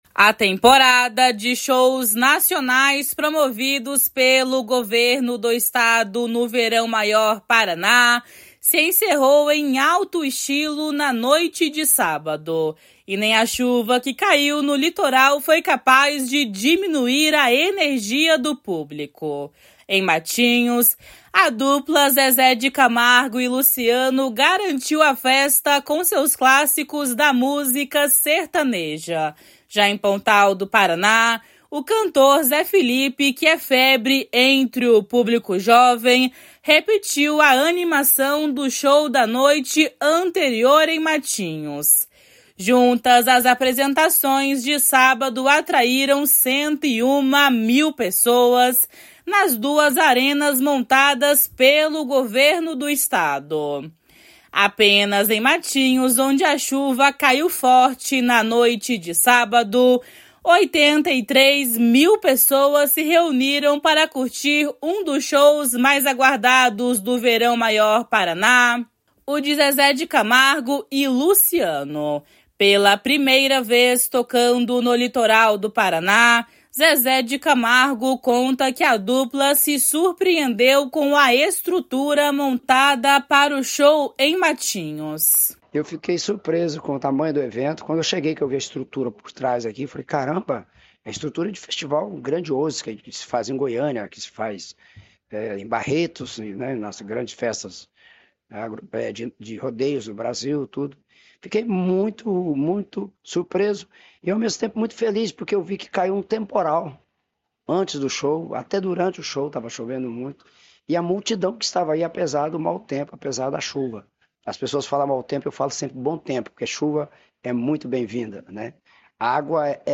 // SONORA ZÉ FELIPE //
O prefeito de Pontal do Paraná, Rudão Gimenes, ressalta o sucesso da temporada 2023/2024 do Verão Maior Paraná.